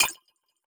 Futuristic Sounds (19).wav